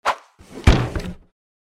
safebox_in.mp3